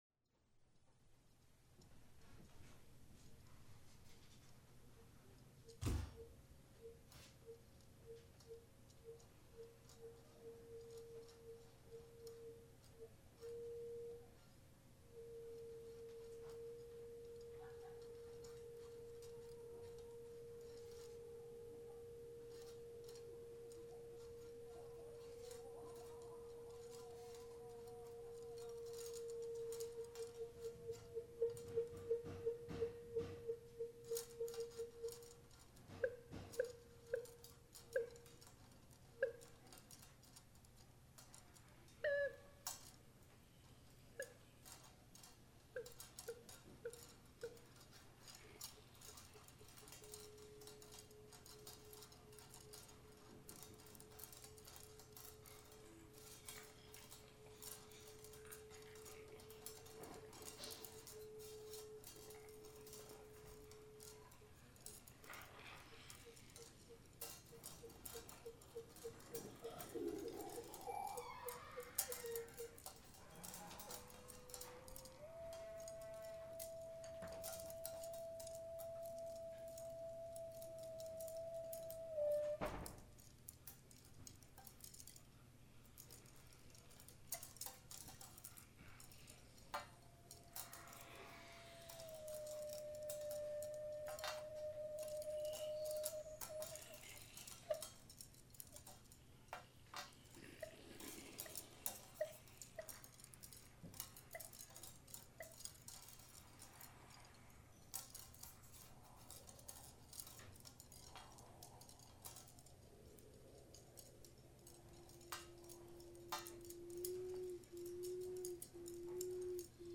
Location: Acadia
Opening set